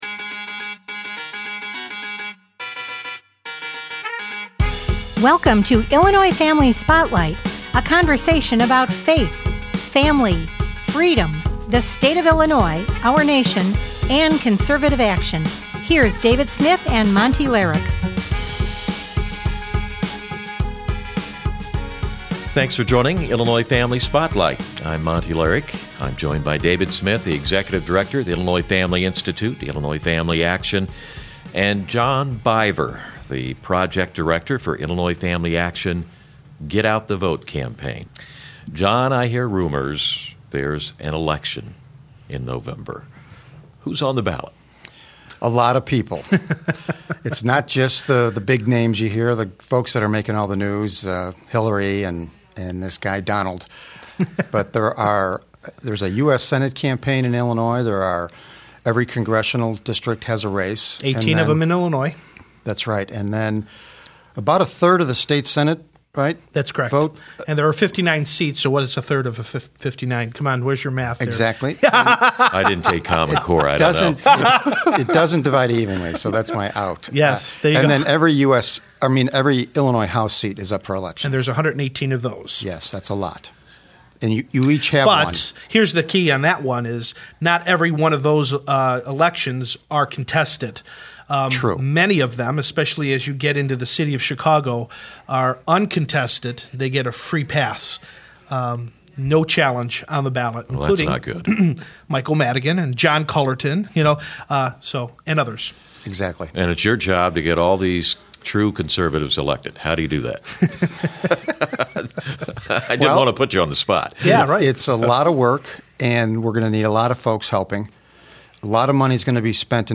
In the first half of the show, the three discuss the opportunities offered through voting by mail. Afterwards, they cover the importance of the upcoming election.